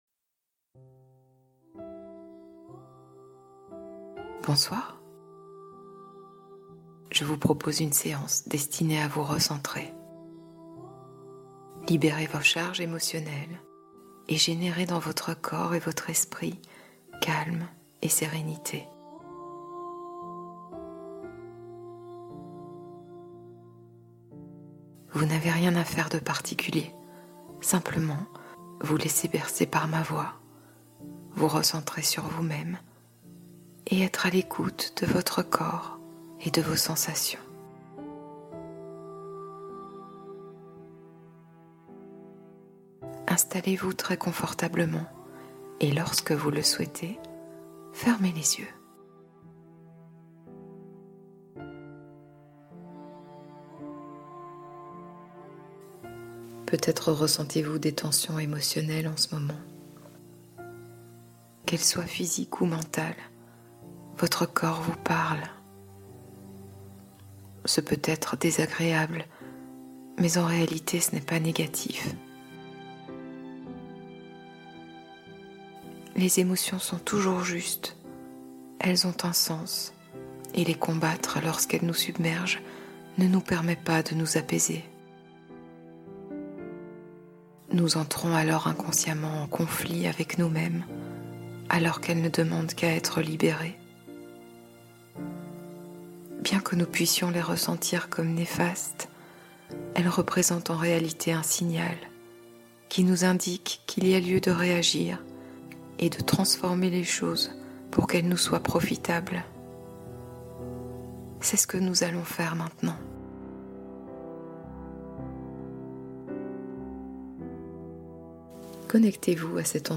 Nuit de Libération : Hypnose nocturne pour dénouer les nœuds émotionnels